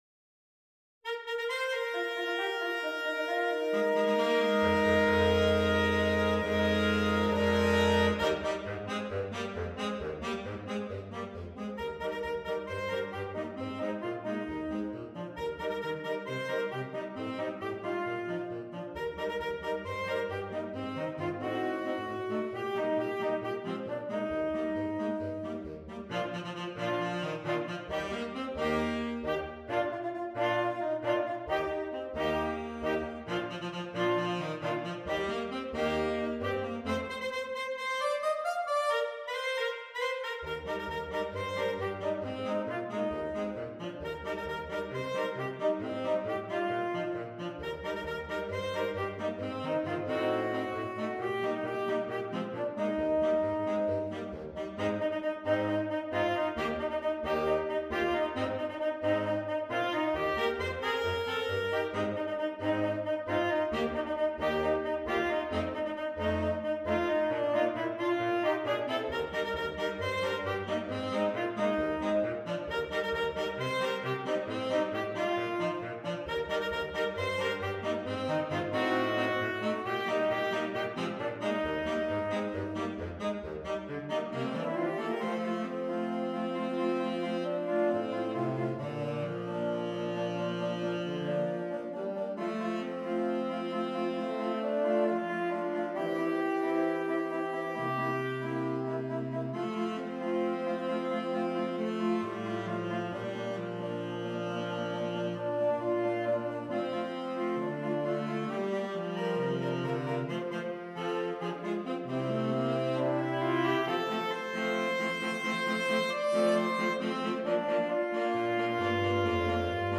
Saxophone Quartet (AATB)